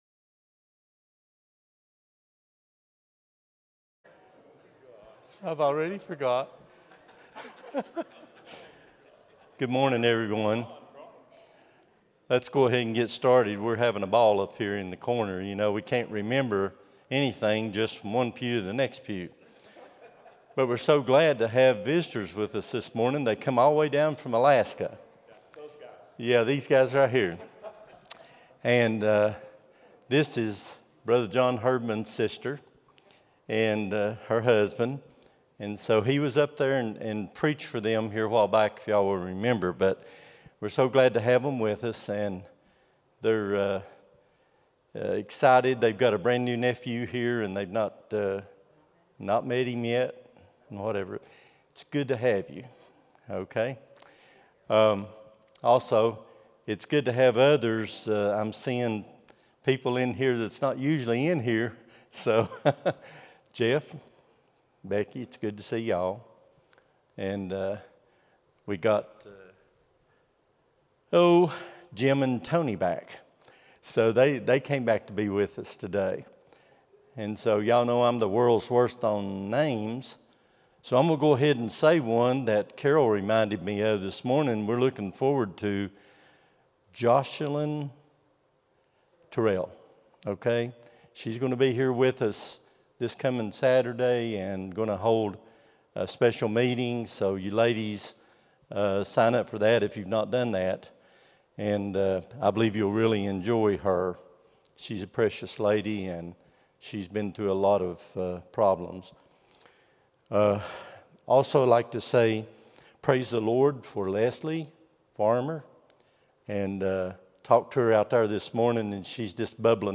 04-06-25 Sunday School | Buffalo Ridge Baptist Church